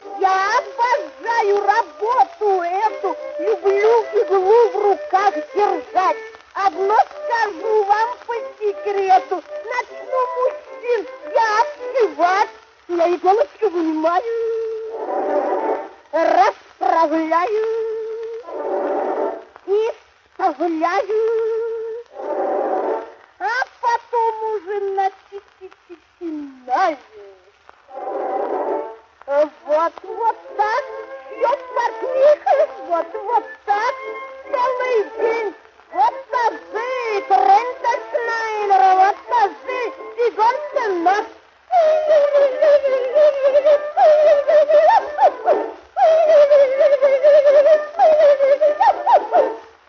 куплеты с названием «Портниха». Грамзапись 1911 года (скачать):